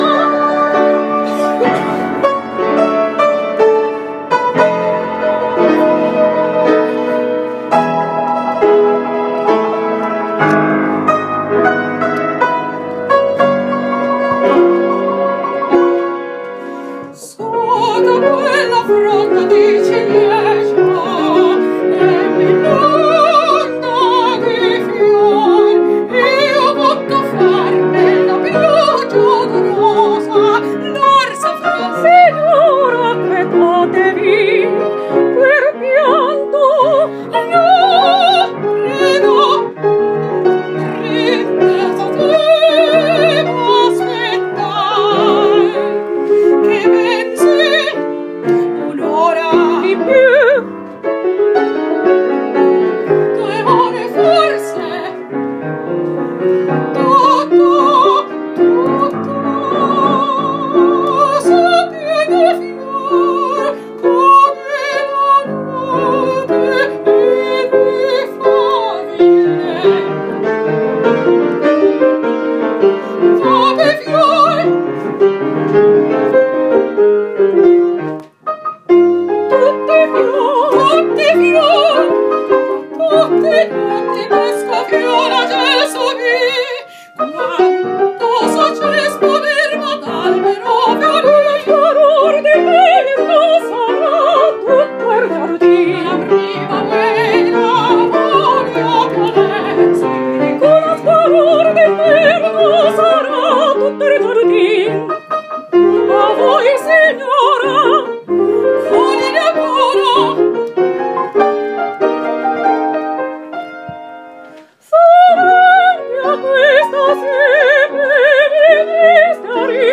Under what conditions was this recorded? This was recorded on my phone from a rehearsal in my early years of study.